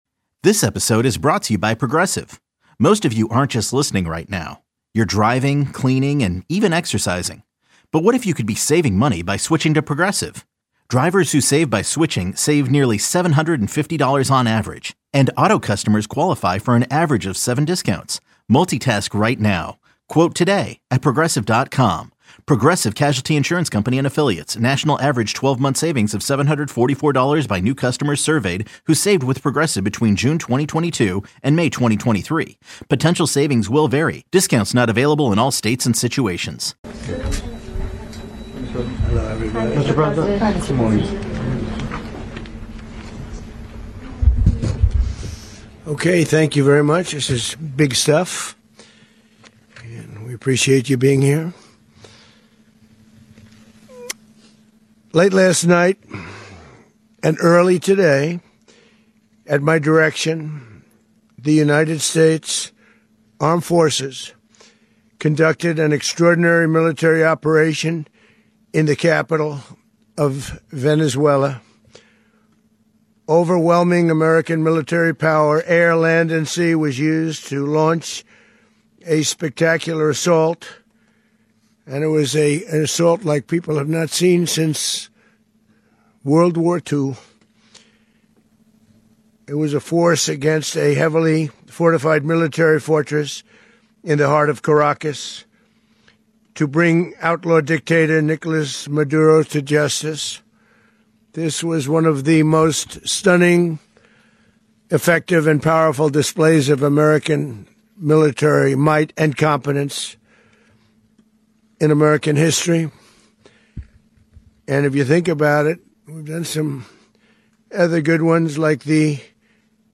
Archive of various reports and news events